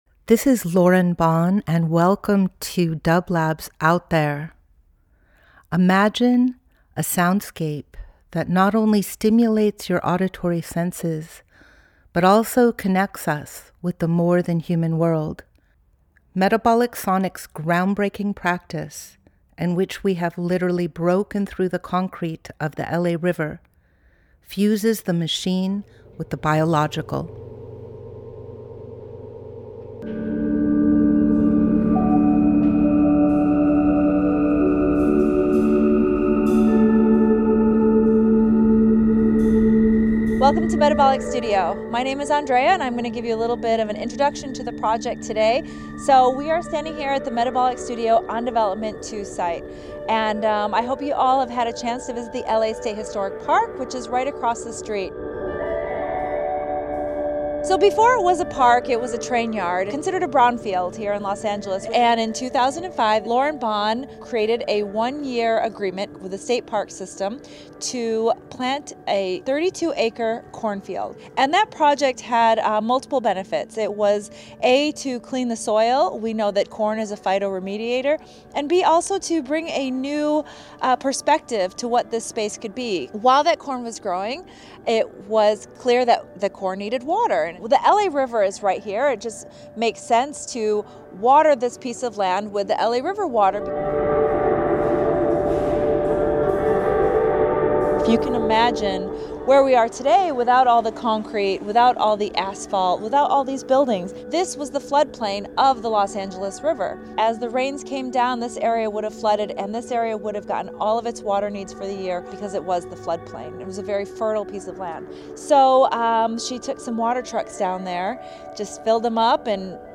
Each week we present a long-form field recording that will transport you through the power of sound.
Today 7 members of the Metabolic Studio team will be sharing information about the UnDevelopment 2 project including: its geological and social history, the current and historic nature of the flora, fauna, and soil in and along the LA River, Metabolic Studio’s experiments in soil remediation, and our practice of working and creating with the public.
Metabolic Studio Out There ~ a Field Recording Program 06.26.25 Ambient Experimental Field Recording Fourth World Voyage with dublab and Metabolic into new worlds.